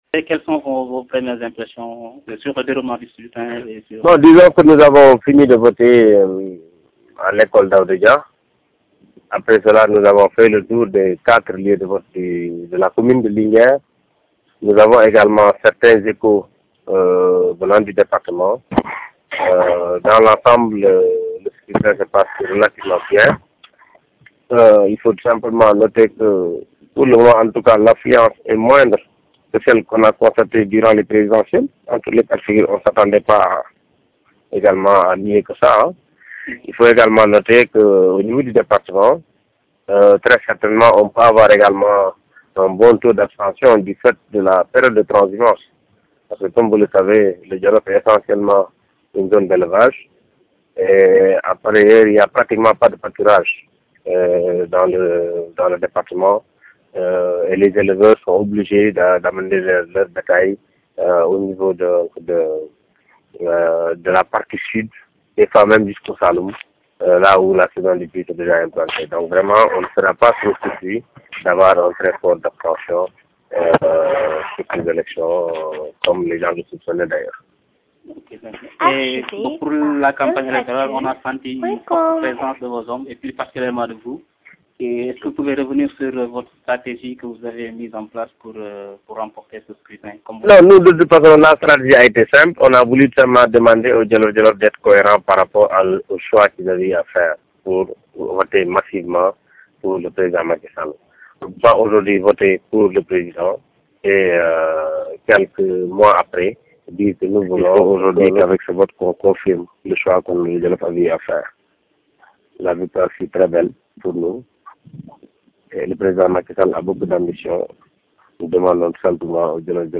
Réaction du ministre Aly Ngouille Ndiaye craint un faible taux de participation (AUDIO)
reaction aly ngouille apres avoir vote.mp3 (2.1 Mo)